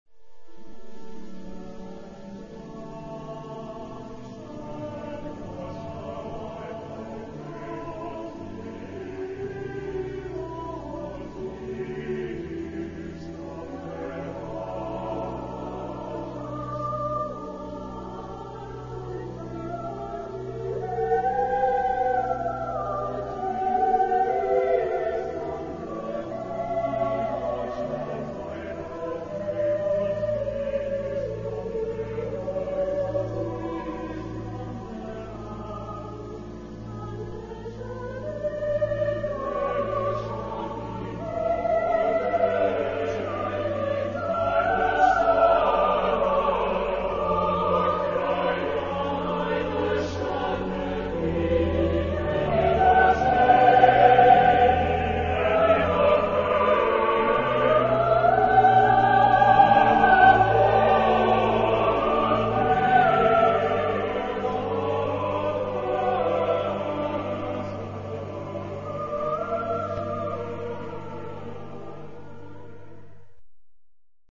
SATB (4 voix mixtes) ; Partition complète.
Hymne (sacré).
Genre-Style-Forme : Sacré ; Hymne (sacré)
Instruments : Orgue (1)
Tonalité : ré mineur ; ré majeur